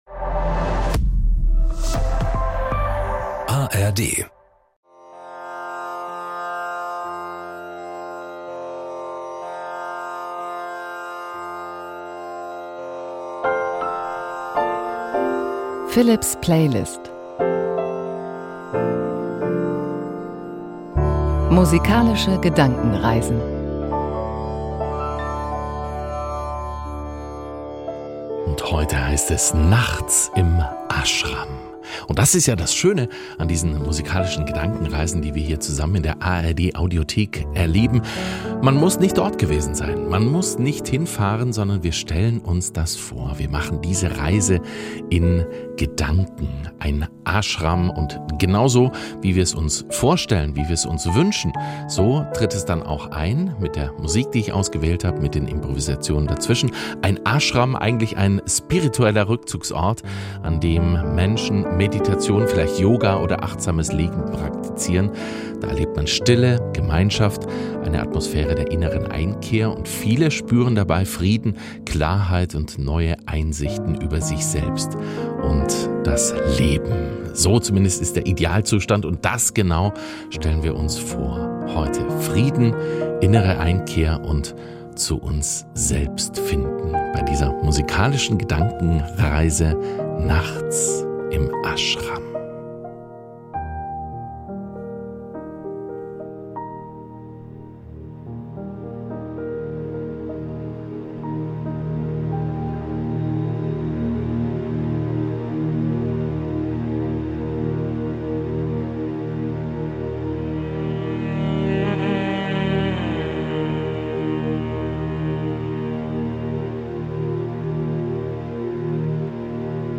Achtsam im Ashram zu Frieden, Klarheit und innerer Einkehr.